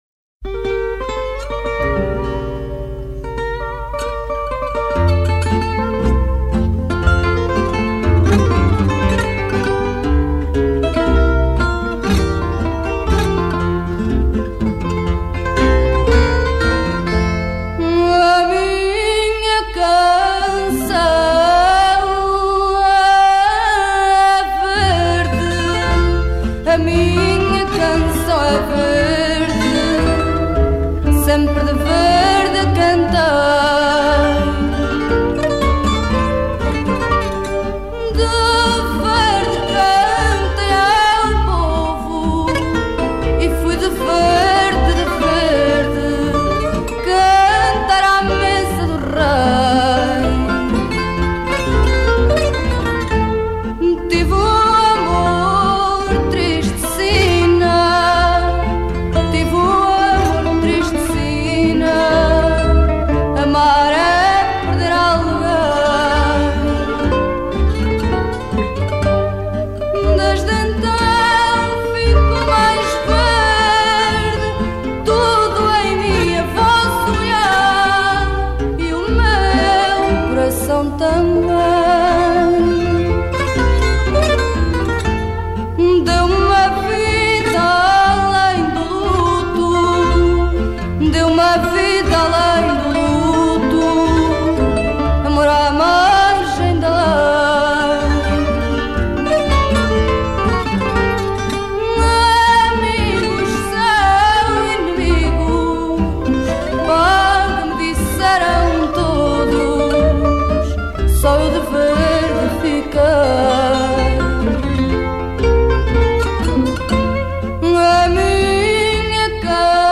Genre: Fado, Folk